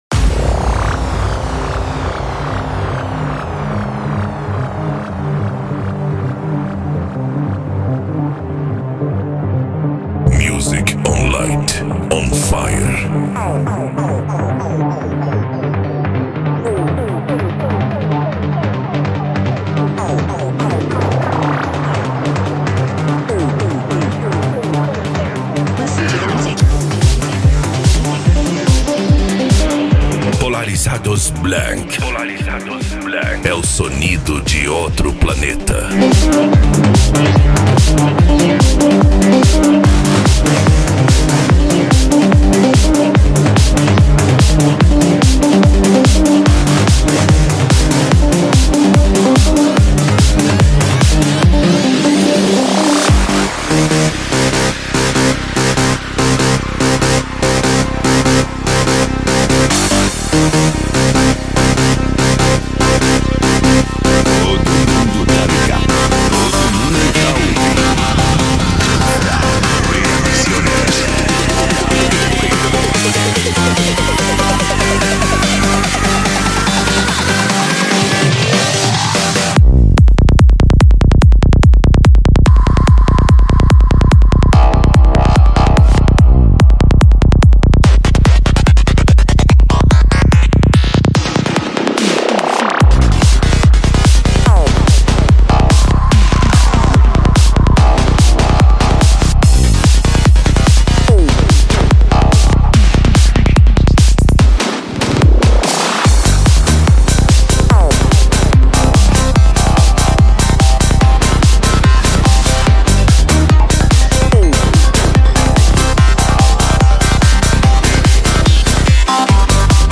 Psy Trance